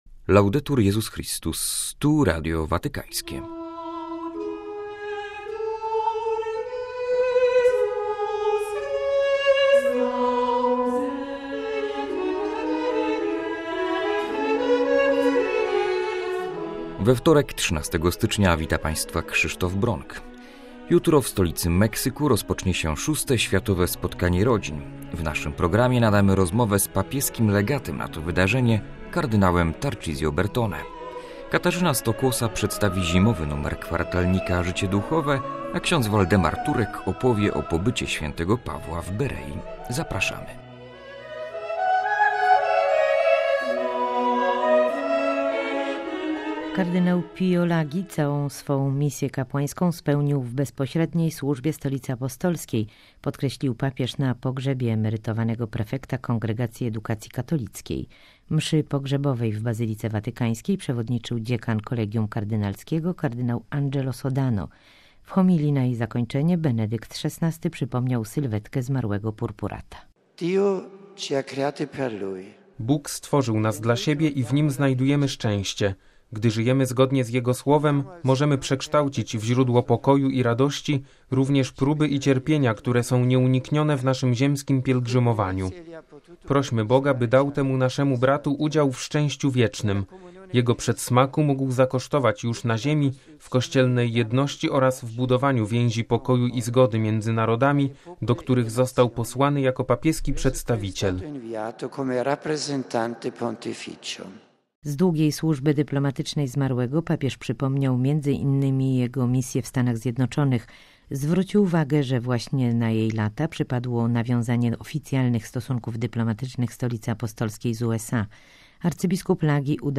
W programie: - aktualności watykańskie; - wywiad z kard. Tarcisio Bertone, legatem papieskim na VI Światowe Spotkanie Rodzin; - prezentacja zimowego numeru kwartalnika „Życie Duchowe” - audycja ks.